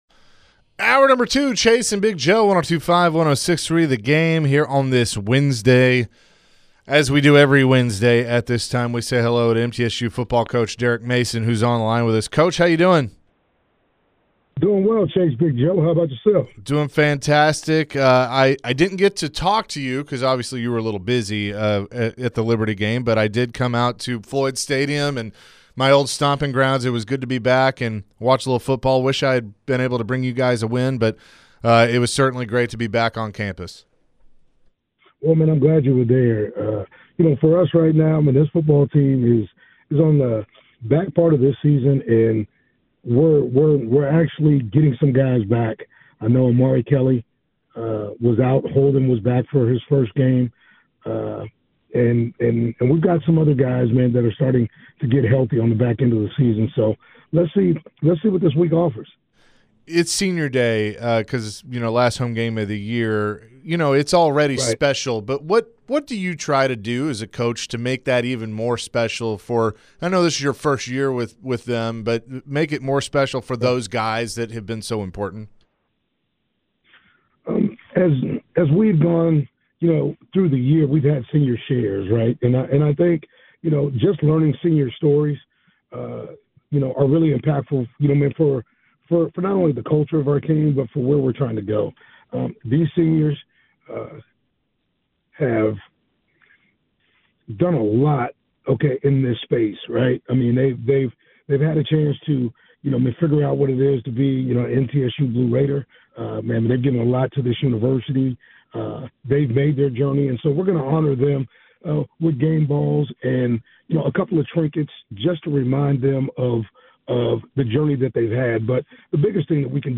MTSU Head Football Coach Derek Mason joined the guys and talked about getting some guys back after losing some key guys earlier in the season. Next, he talked about Senior Day coming up and how they impacted the team and their journey.